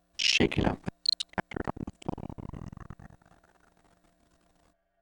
Processing: granulated, KS = 305, F=980, 2:3, then 20:1, F=1000